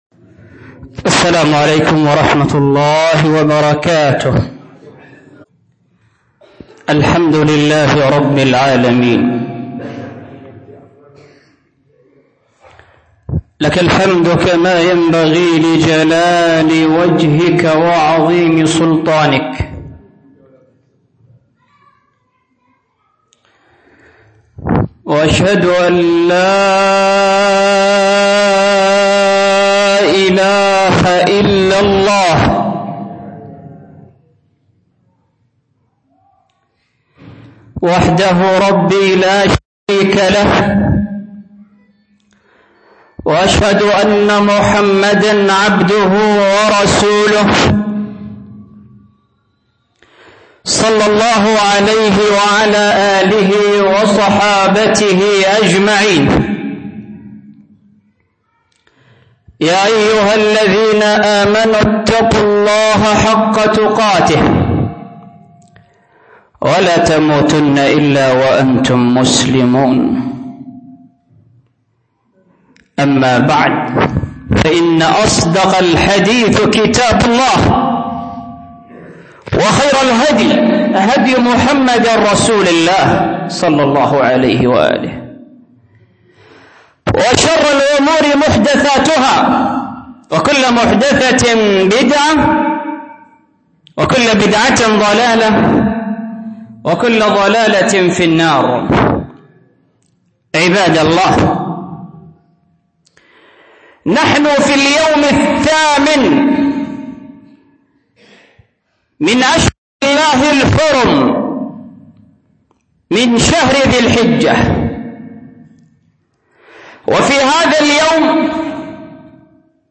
خطبة جمعة